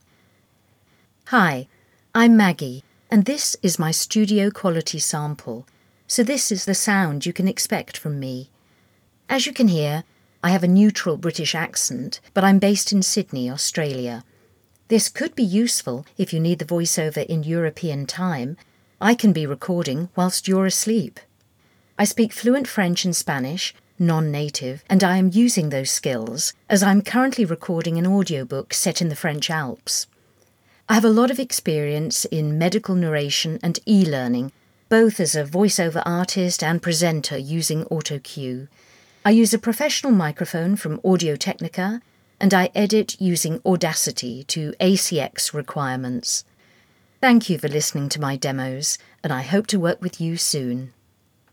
Female
Warm, mature, and versatile, my voice is clear and articulate with a neutral British accent.
Studio Quality Sample
A Sample From My Studio